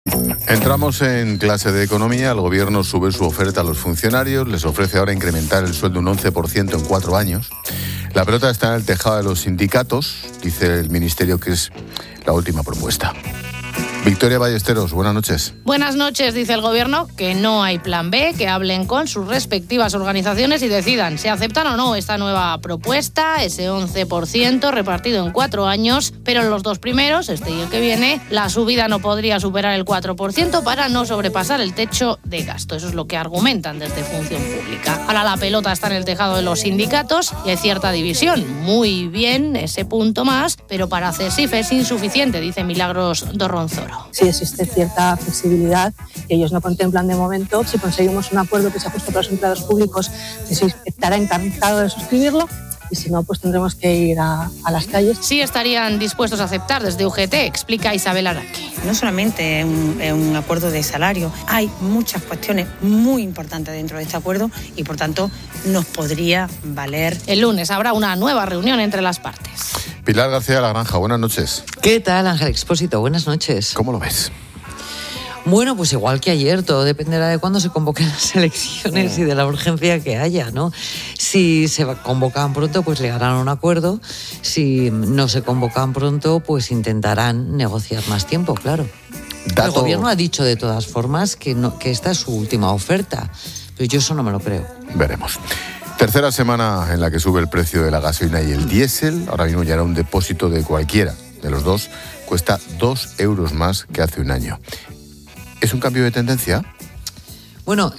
Este escenario de negociación ha sido analizado en el programa 'La Linterna' de COPE por Ángel Expósito